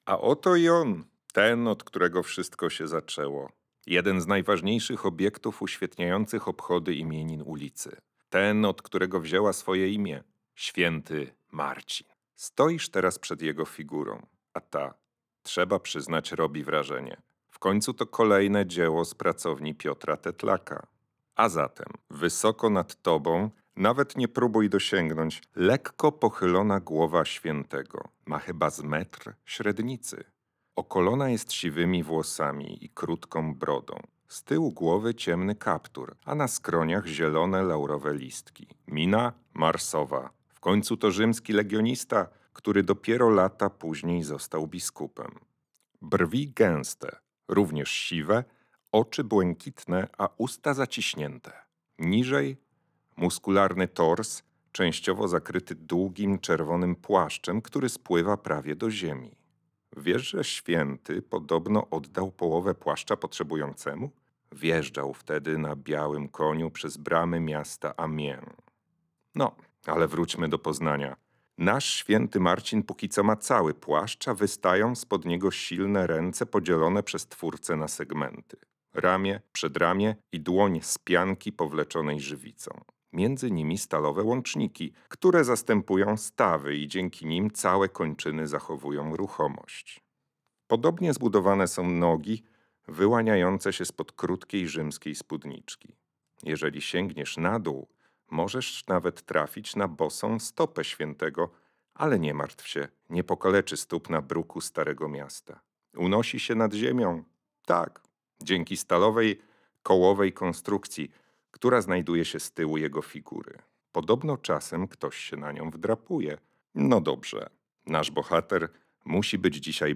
Święty Marcin – audiodeskrypcja